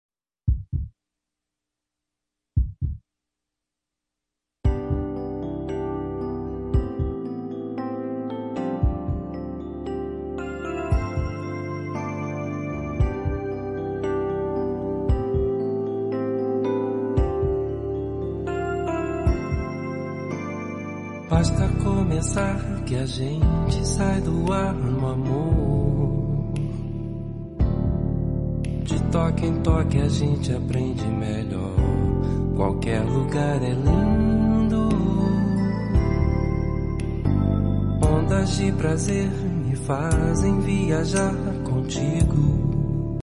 With a velvet voice gives us a romantic journey.